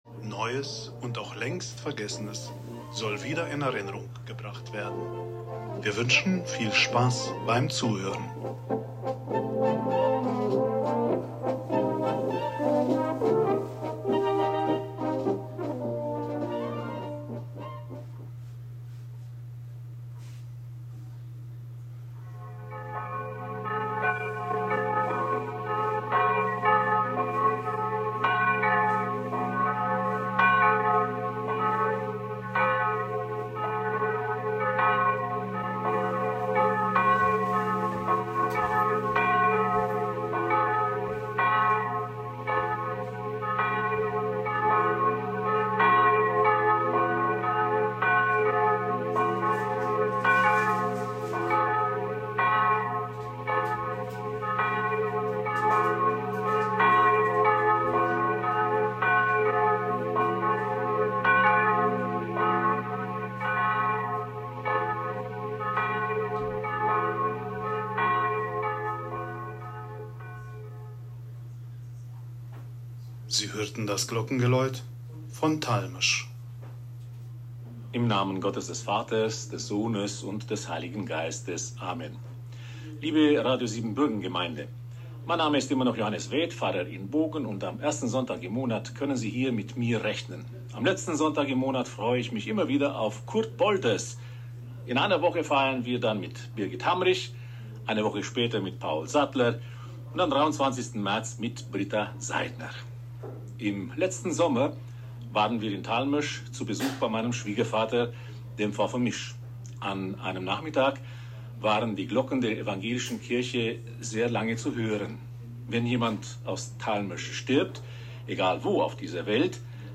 Am Sonntag, den 02. März 2025, war um 10.00 Uhr ein besonderer Gottesdienst mit dem feierlichen Glockengeläut und einer Kurzvorstellung unserer Heimatgemeinde Talmesch auf Radio Siebenbürgen zu hören.